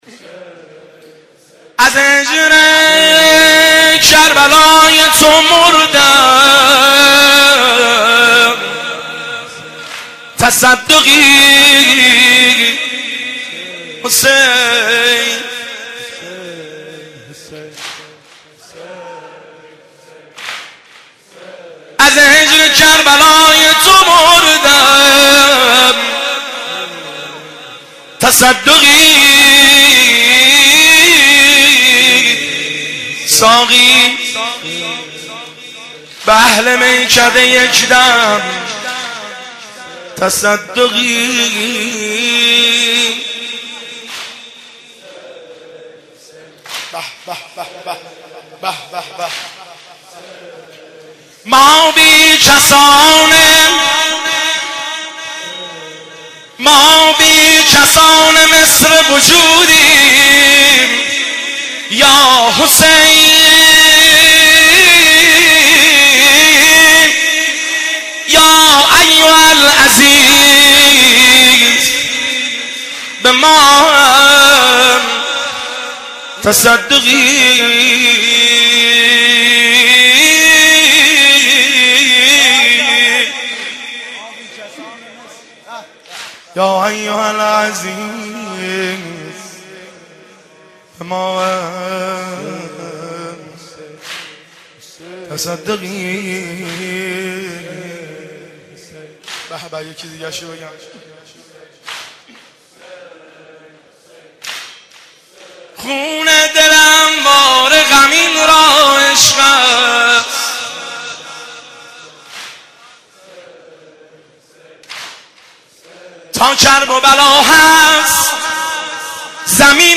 زمزمه و شعرخوانی – شب 8 محرم الحرام 1390